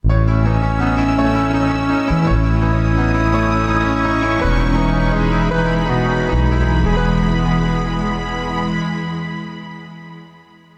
Ráfaga musical. Cadencia.
cadencia
melodía
Sonidos: Música